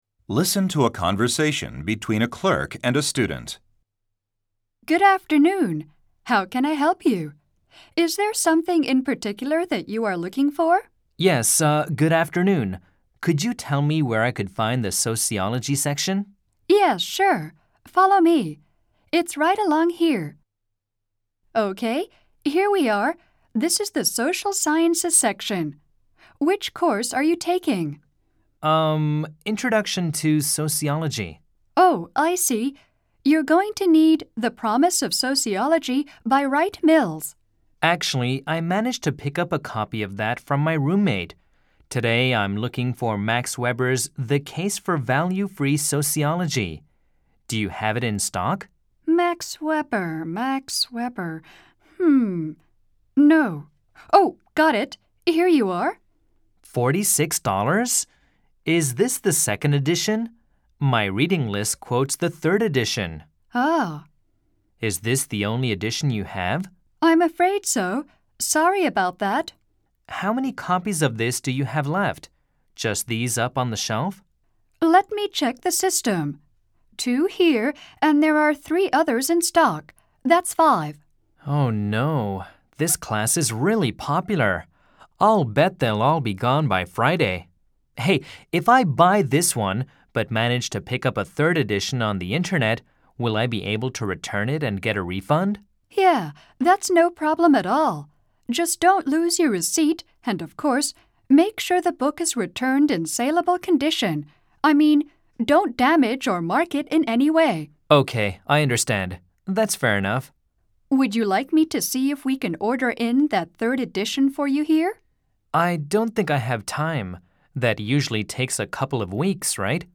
4　第5章　TOEFL iBT リスニング：会話（書籍p.141～144）